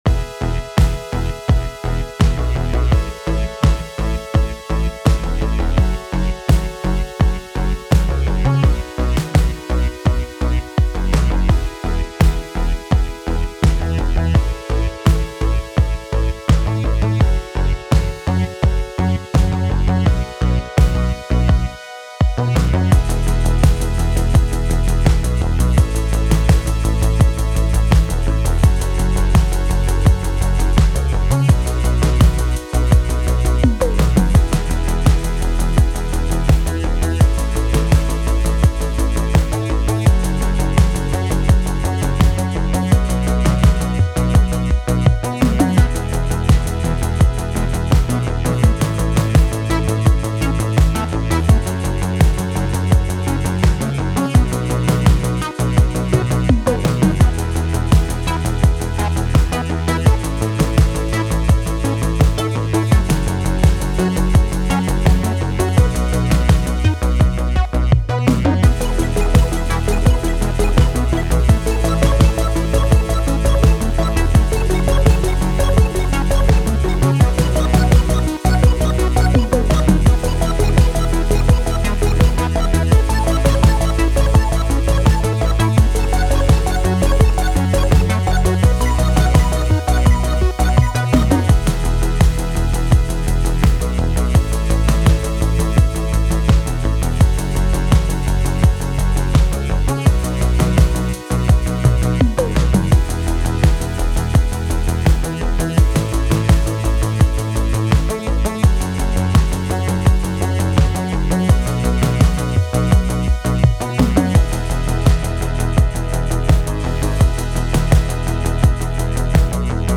DN2 + Pro-800 + Blofeld.
It’s too cool to route a different osc to a parallel filter and pan it. In this case it’s a square osc octave higher with a bandpass type (for the bass sound). Modifiers are also too cool, you can get crazy stepped modulations with them.